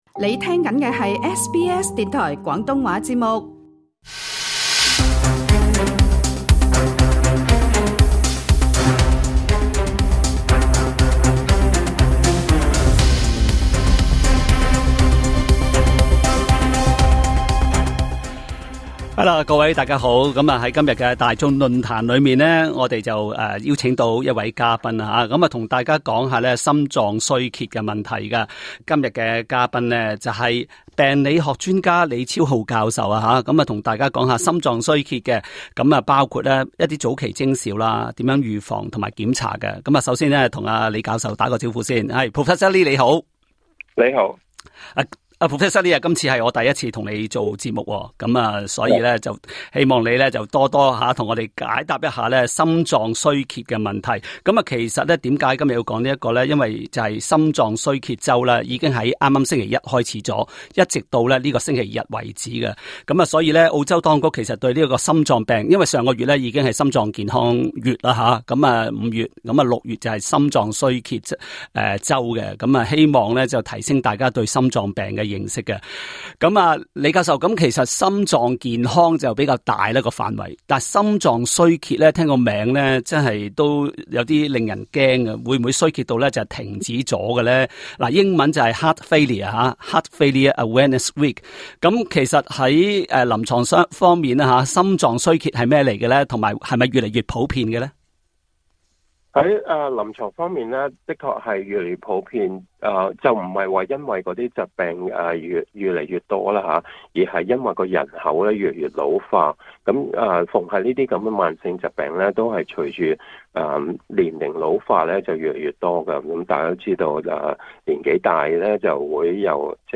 他還解答多位聽眾致電提問。